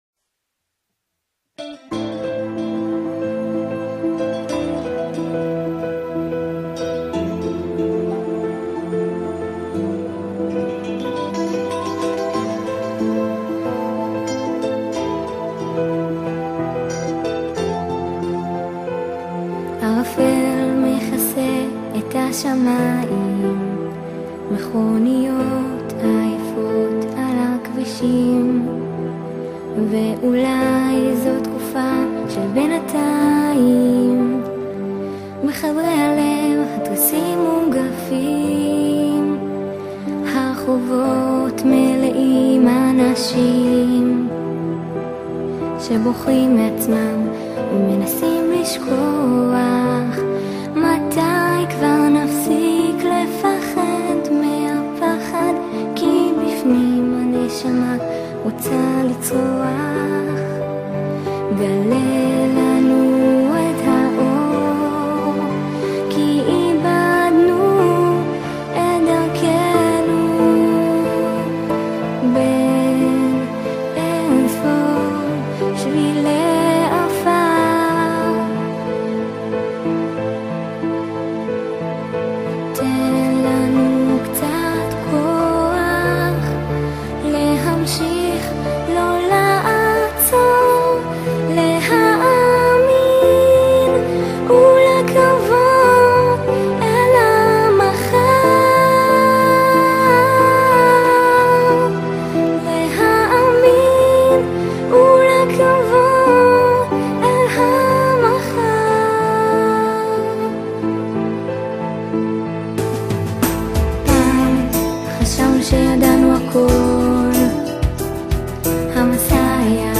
קלידים וגיטרות